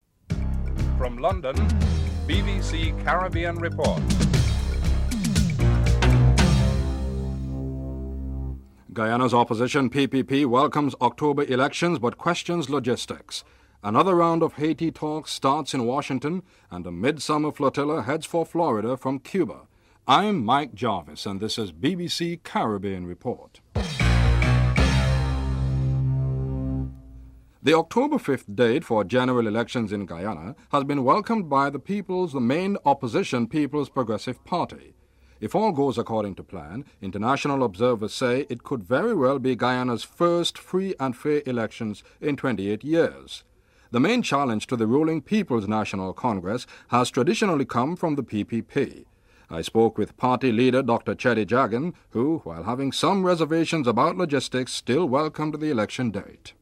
1. Headlines (00:00-00:26)
2. Guyana’s main opposition party leader Dr. Cheddi Jagan talks about the upcoming October 5th elections. (00:27-02:53)
8. Myles Frechette, US Assistant Trade Representative for the Caribbean and Latin America, warns that increased competitiveness and not NAFTA will attract investment. (12:29-14:28)